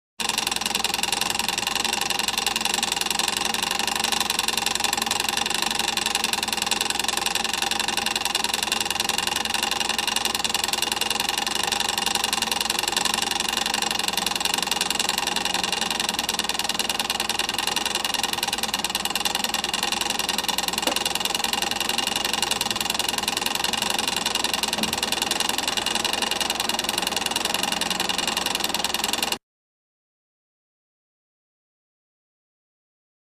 Egg Timer With Rapid Clicks.